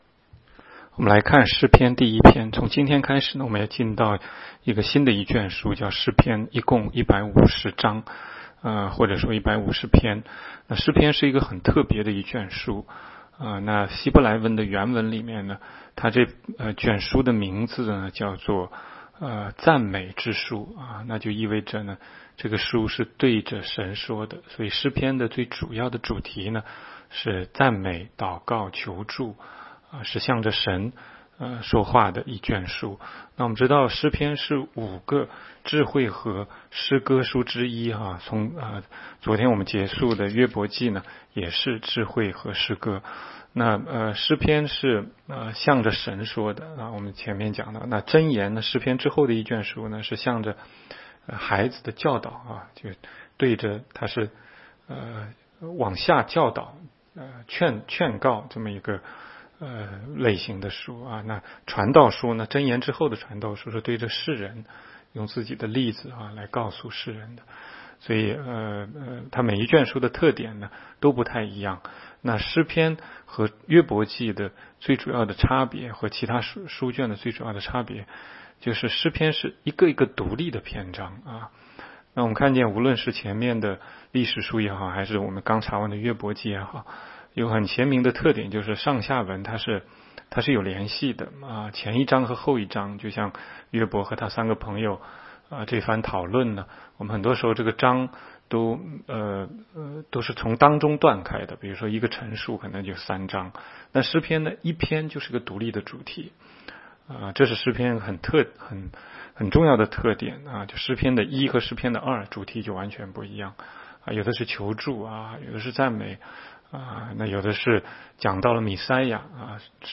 16街讲道录音 - 每日读经-《诗篇》1章